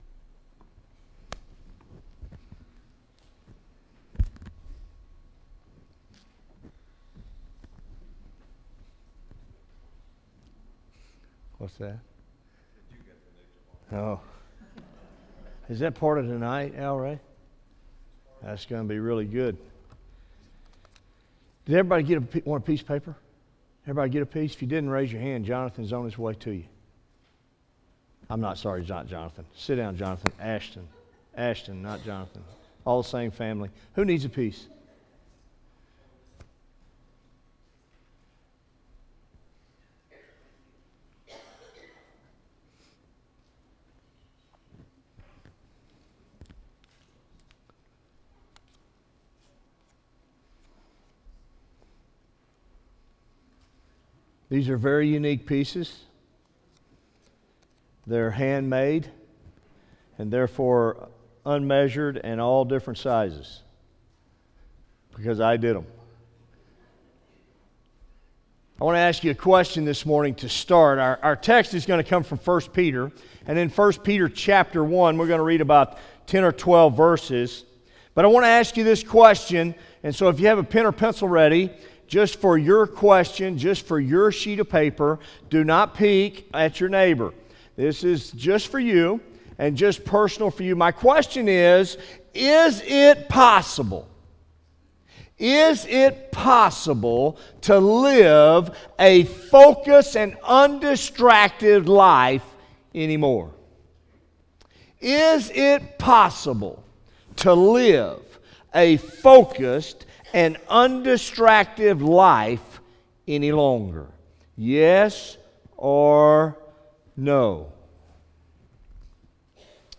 by Office Manager | Apr 10, 2017 | Bulletin, Sermons | 0 comments